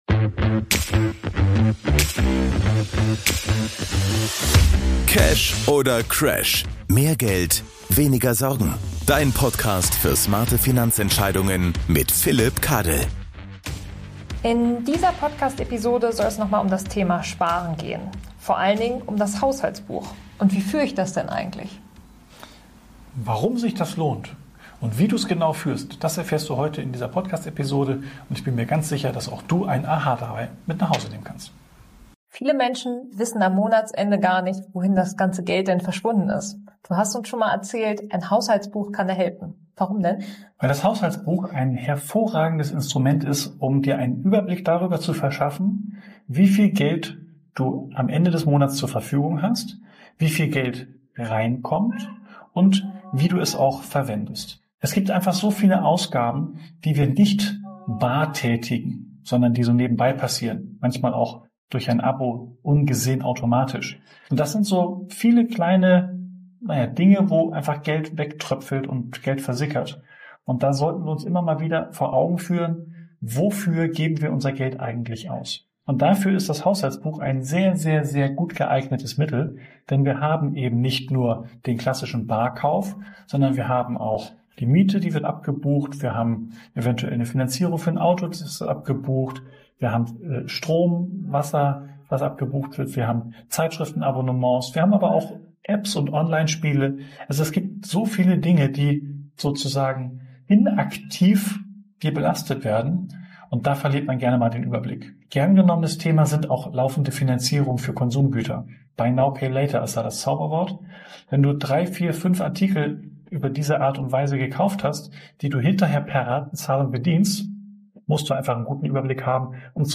in diesem Interview, warum ein Haushaltsbuch der einfachste und